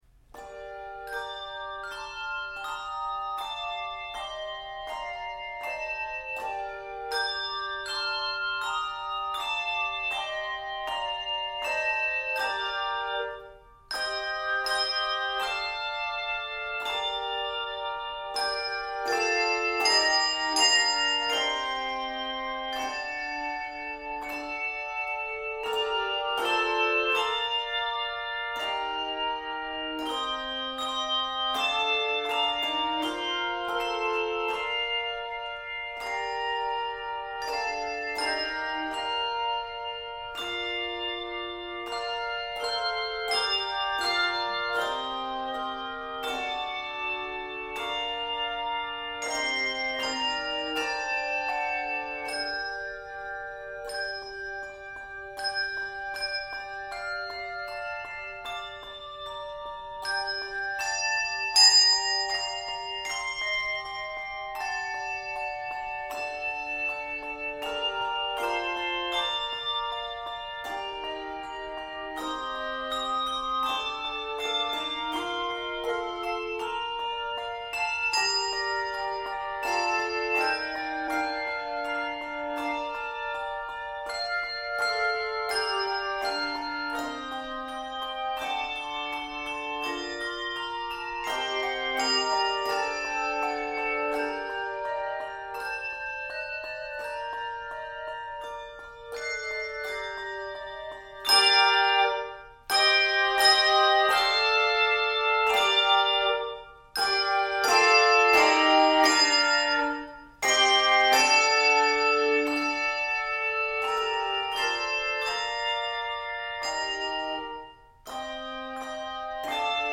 Key of g minor.